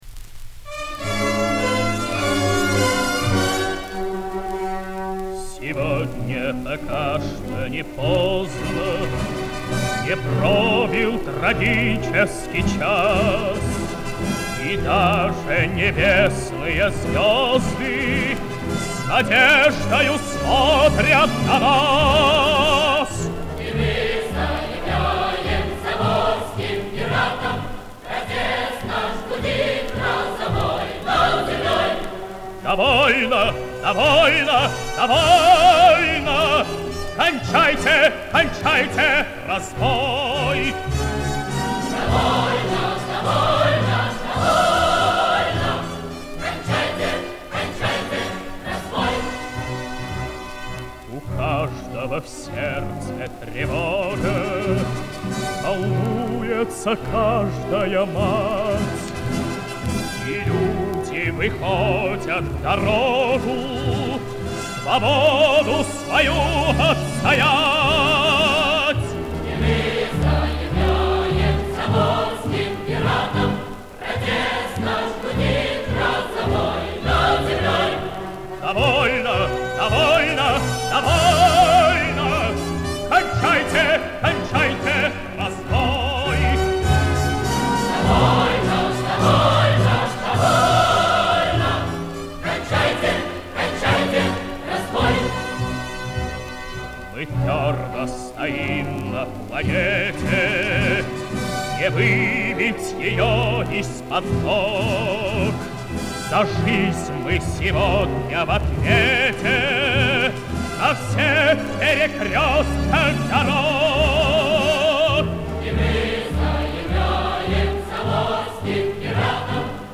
Улучшение качества